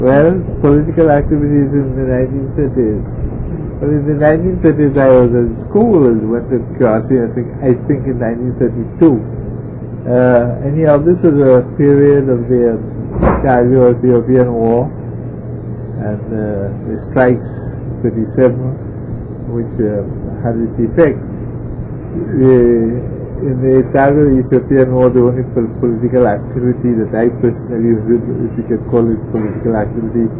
3 audio cassettes